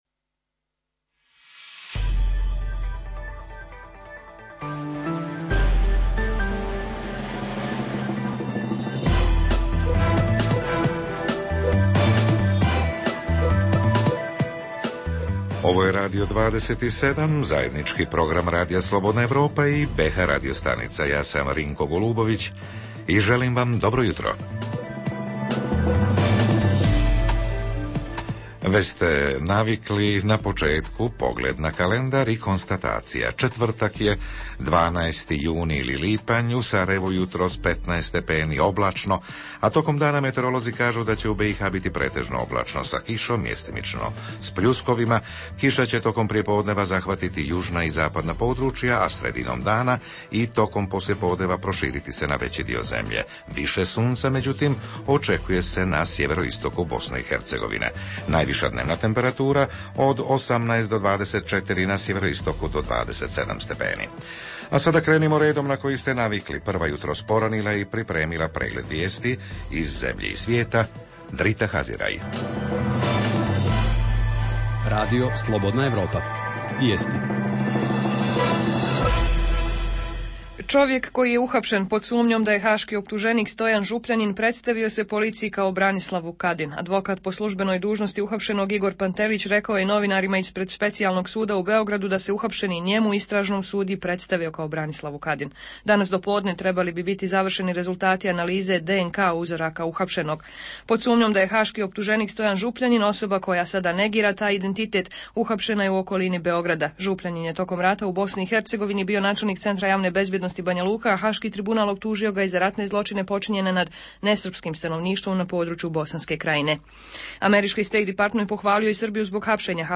Redovni sadržaji jutarnjeg programa za BiH su i vijesti i muzika.